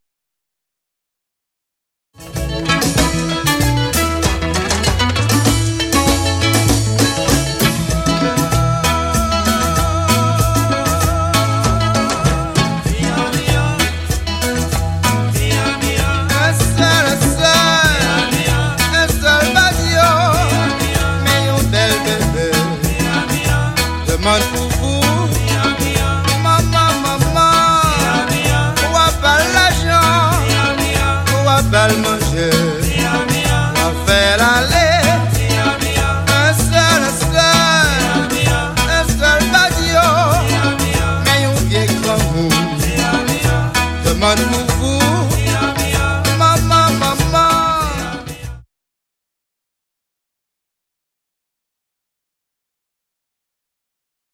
konpa manba